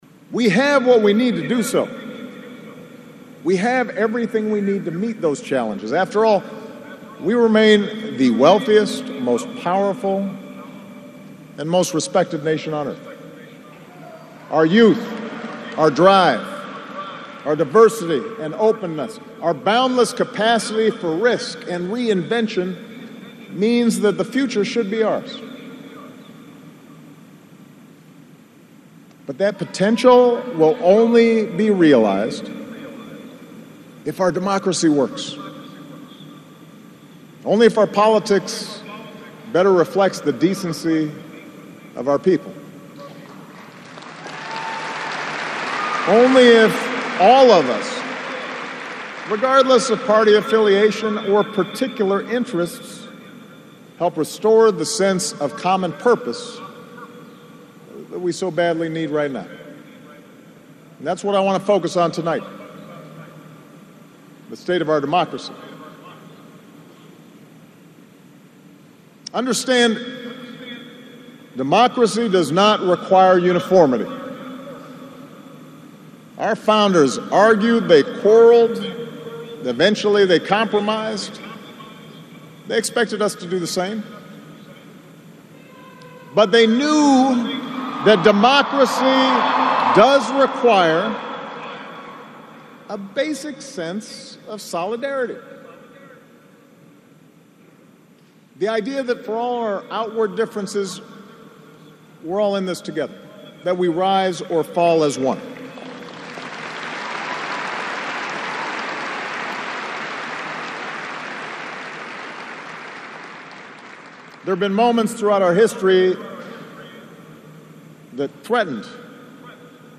美国总统奥巴马告别演讲(5)